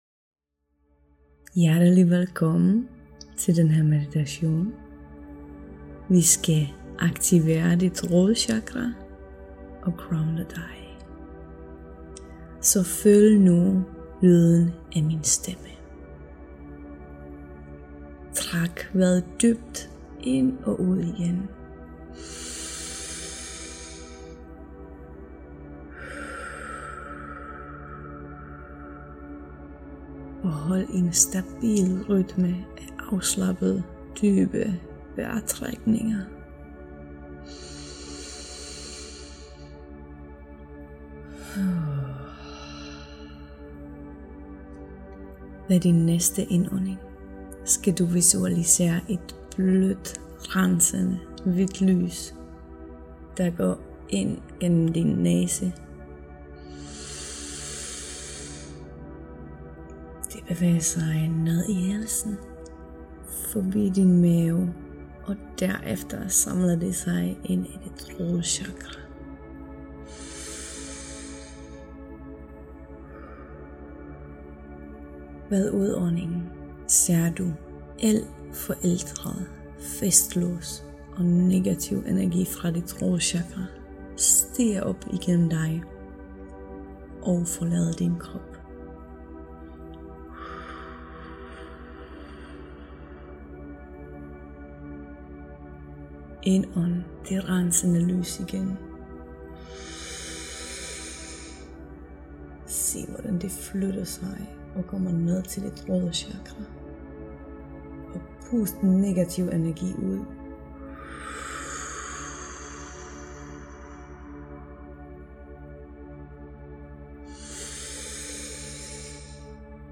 ROD CHAKRA meditation
Den guidede meditation tager ca. tre minutter, hvorefter musikken fortsætter op til otte minutter, så du kan nyde øjeblikket, hvis du har behov for det.